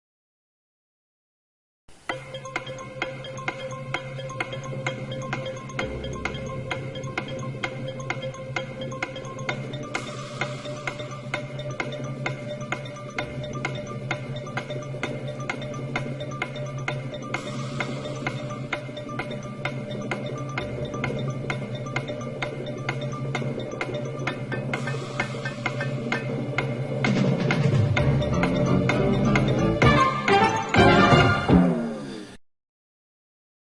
Countdown_clock.mp3